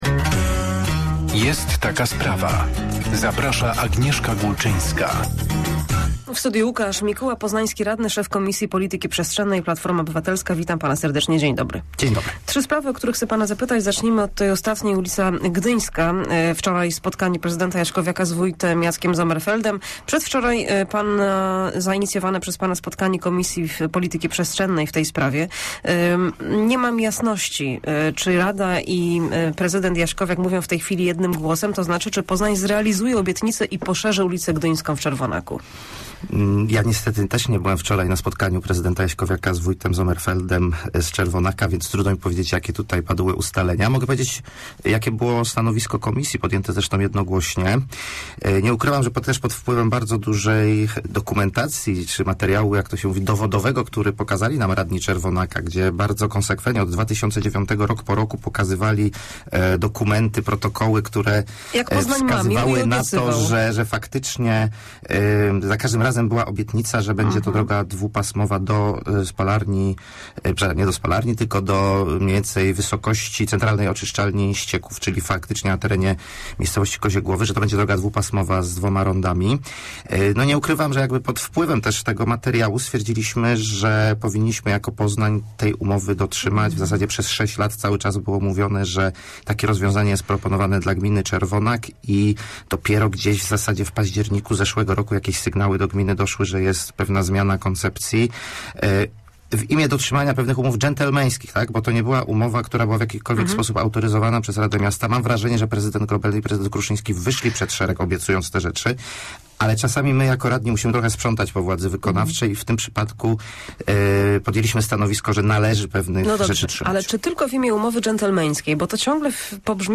Zamieszanie wokół GOAP i spalarni śmieci skomentował na antenie Radia Merkury miejski radny Łukasz Mikuła.